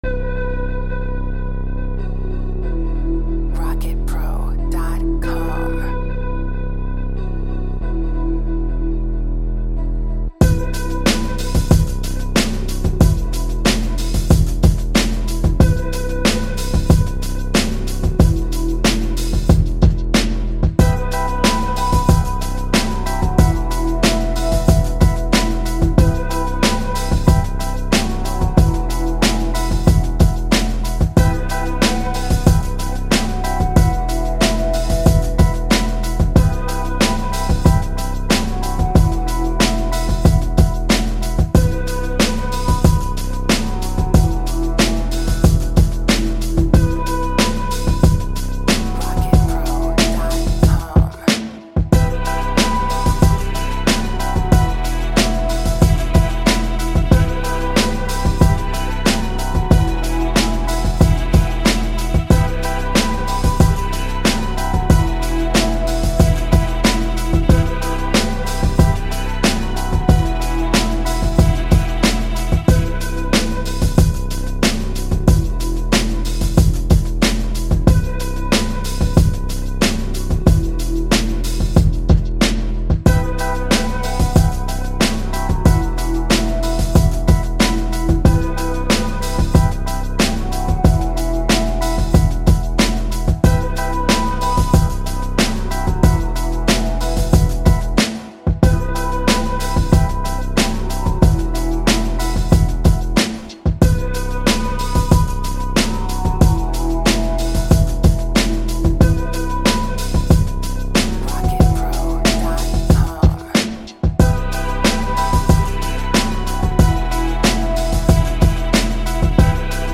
92.5 BPM.
underground drums, synths, and staccato organ chords.
East Coast
Gangsta/Street